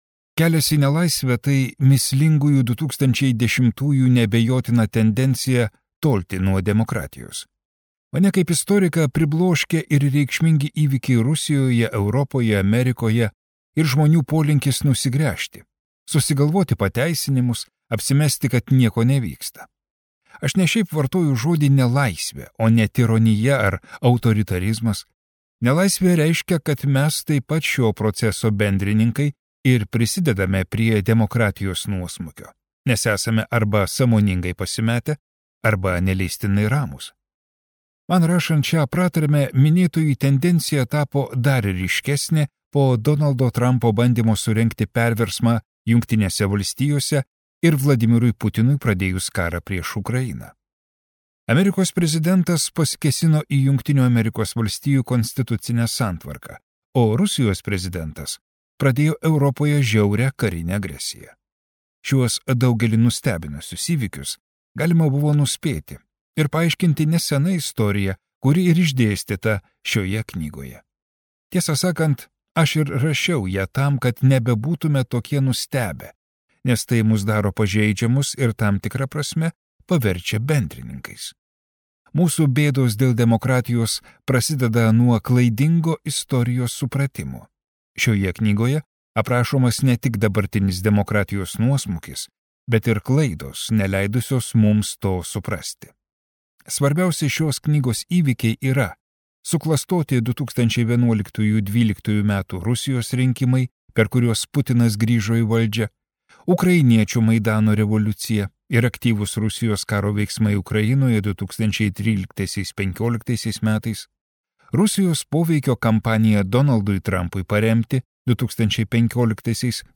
Kelias į nelaisvę: Rusija, Europa, Amerika | Audioknygos | baltos lankos